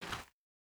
Bare Step Gravel Medium B.wav